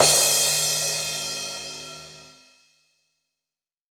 PCRASH 1P.wav